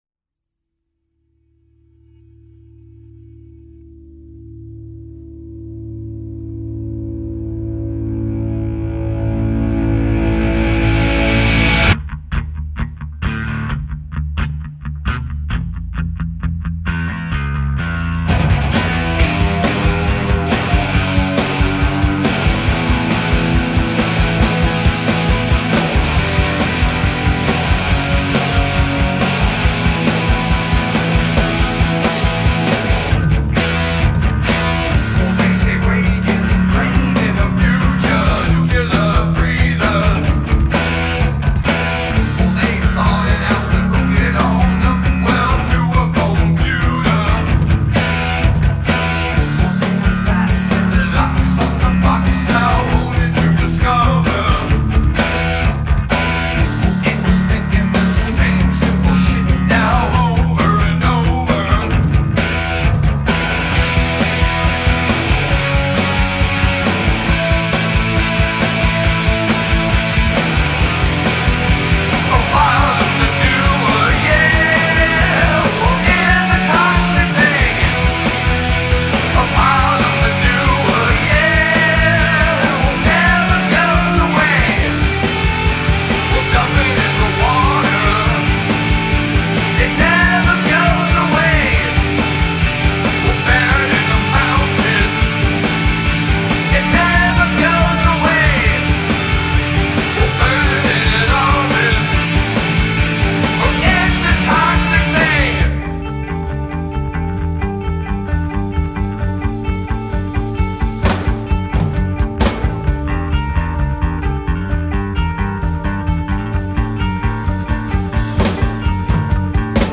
Guitar, Vocals
Bass, Vocals
Drums